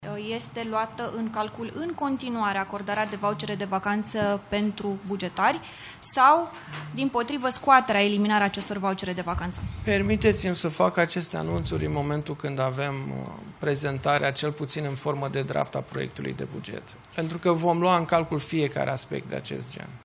Acordarea voucherelor de vacanță este pusă sub semnul întrebării. Întrebat de jurnaliști dacă bugetul pe anul 2026 prevede acordarea în continuare a acestor facilități, ministrul de Finanțe a evitat să dea un răspuns clar.
Ministrul de Finanțe, Alexandru Nazare: Voi face aceste anunțuri când vom avea prezentarea proiectului de buget